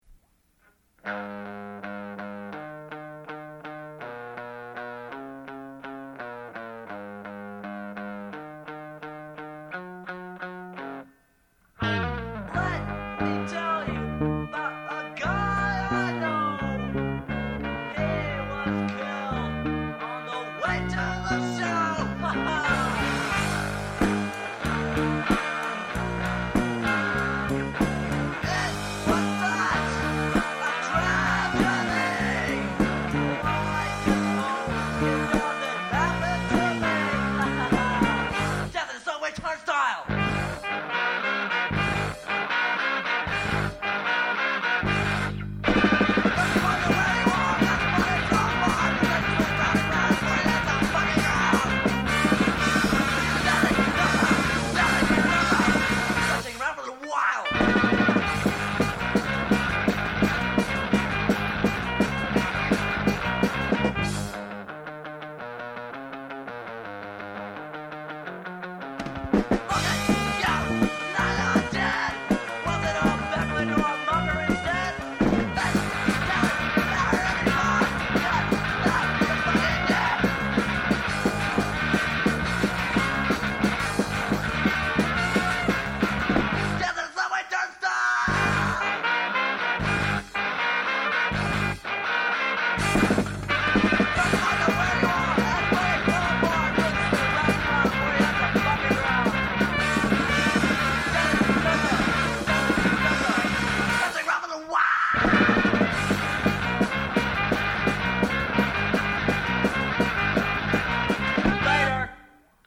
on drums